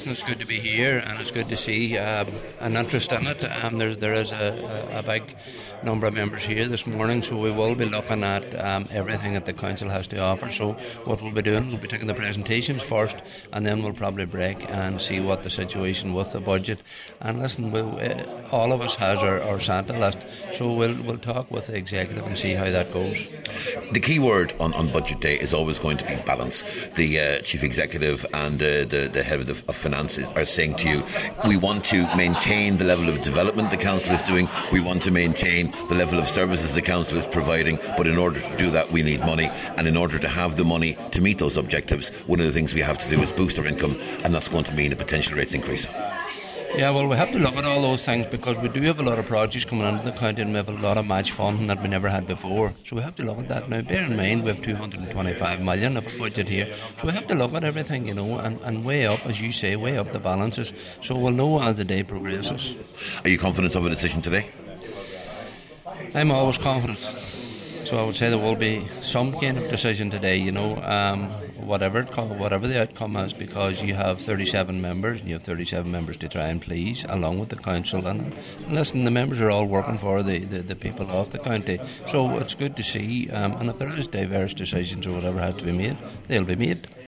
Speaking before he opened the meeting, Cathoirleach Cllr Paul Canning told Highland Radio News that while there are potentially lengthy discussions ahead, he’s confident a decision will be reached today………